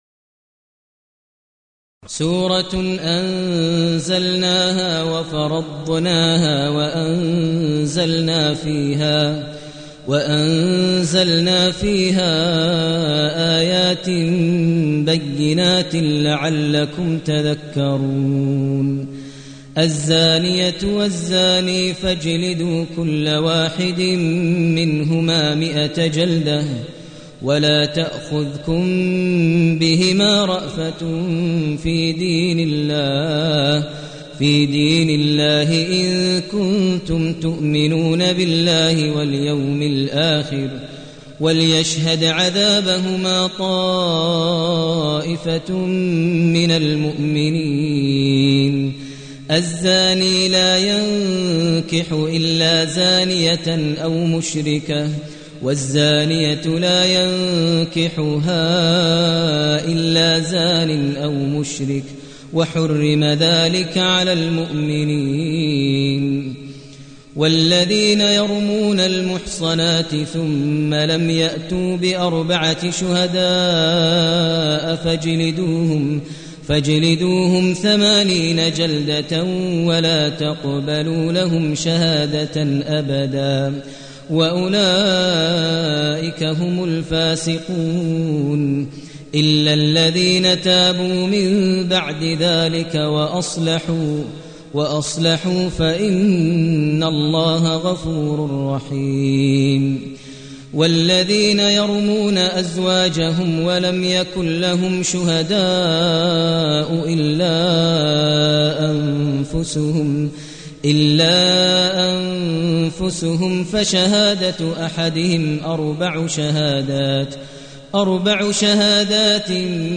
المكان: المسجد النبوي الشيخ: فضيلة الشيخ ماهر المعيقلي فضيلة الشيخ ماهر المعيقلي النور